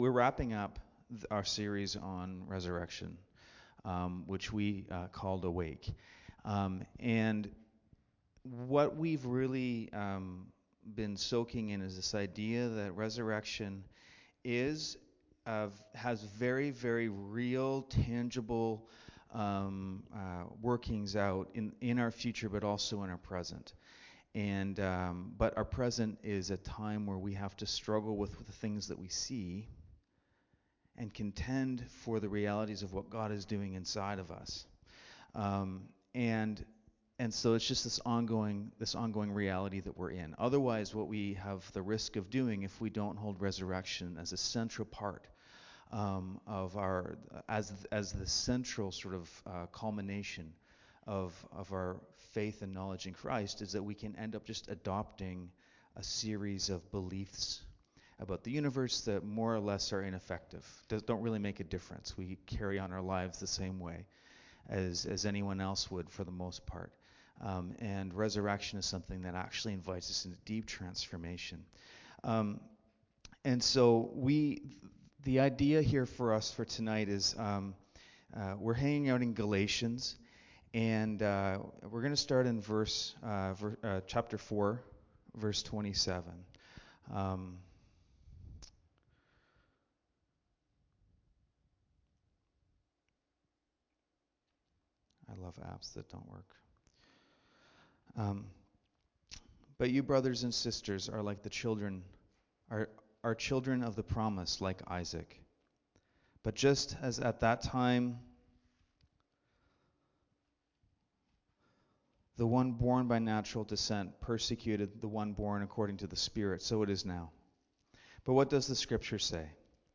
1-14 Service Type: Friday Nights Bible Text